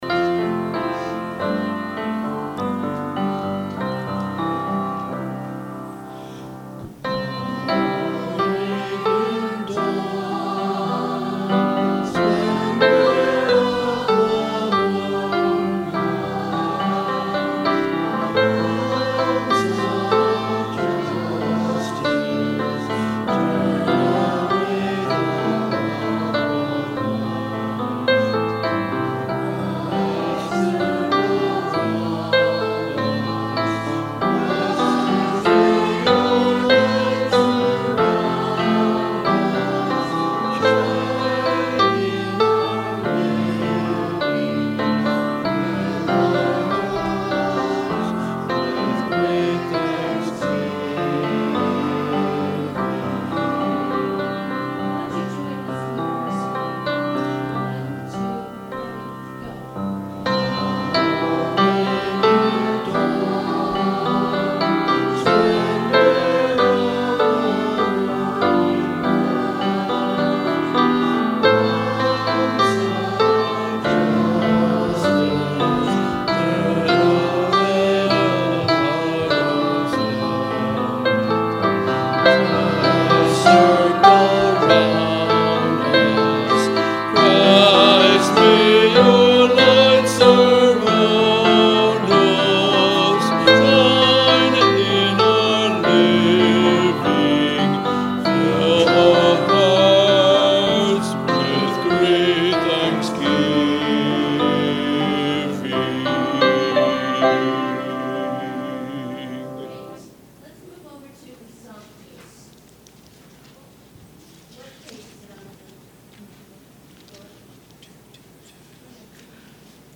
Sermon – December 2, 2018
advent-sermon-december-2-2018.mp3